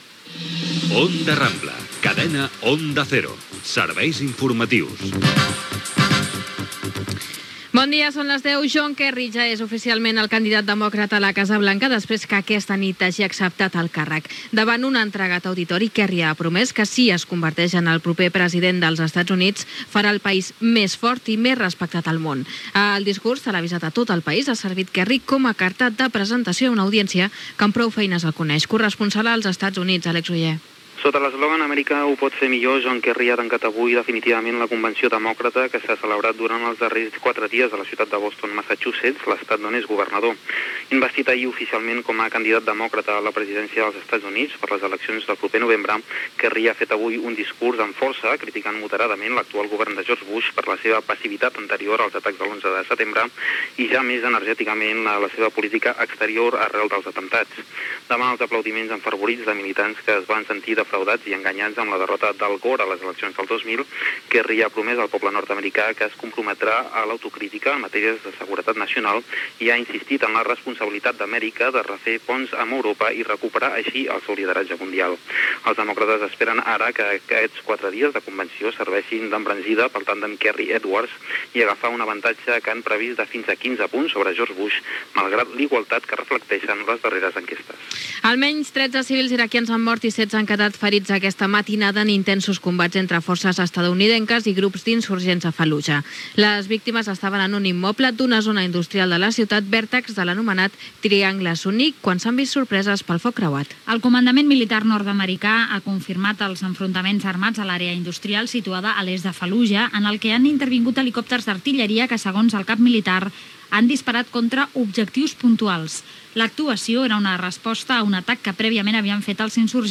Idicatiu de l'emissora, careta del programa, John Kerry candidat a la presidència dels EE.UU., visita del secretari d'estat nord-americà a Bagdad, incendi a Andalusia. Publicitat, indicatiu, esports, indicatiu i publicitat
Informatiu
FM